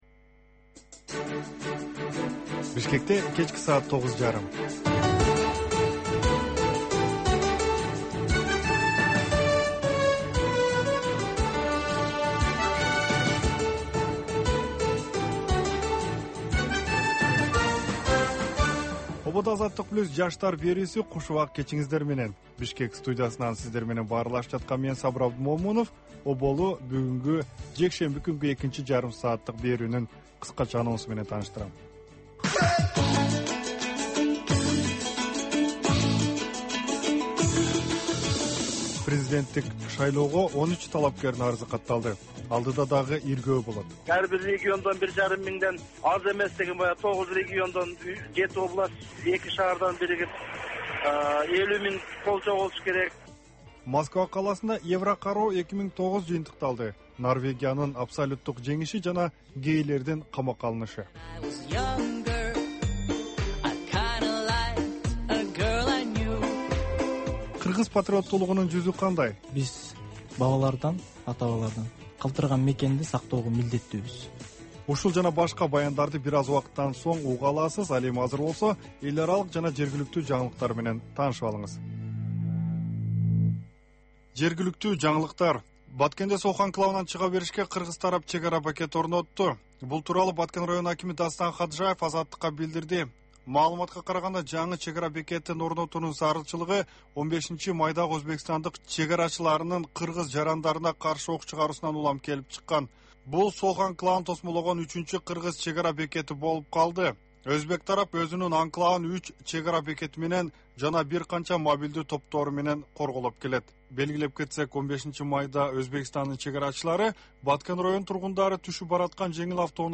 Бул жаштарга арналган кечки үналгы берүү жергиликтүү жана эл аралык кабарлардан, репортаж, маек, баян жана башка берүүлөрдөн турат. "Азаттык үналгысынын" бул жаштар берүүсү Бишкек убактысы боюнча саат 21:30дан 22:00ге чейин обого чыгат.